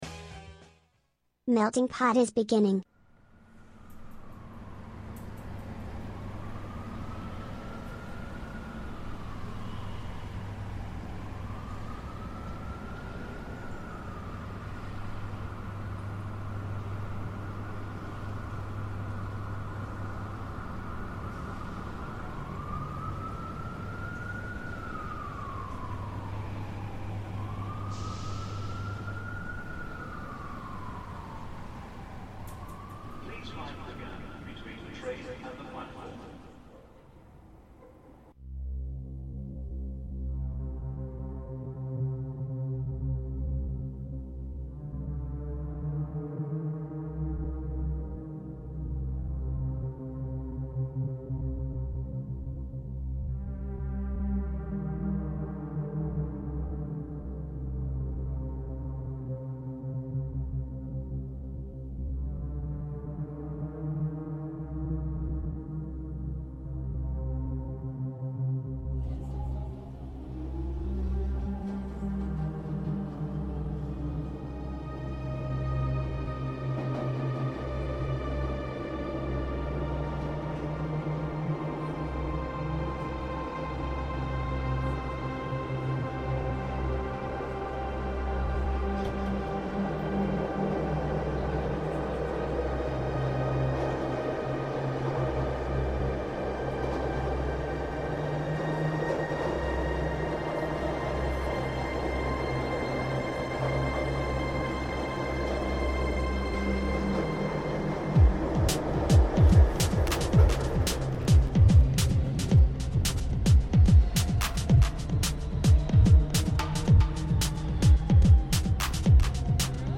Brani LIVE